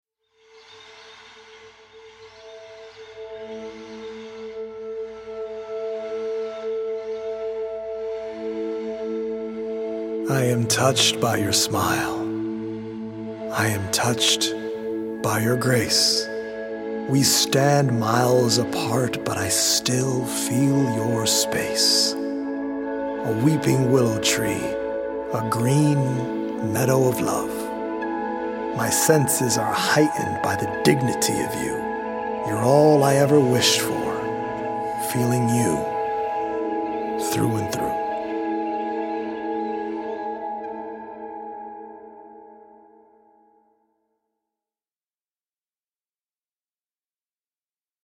original poems written/performed
healing Solfeggio frequency music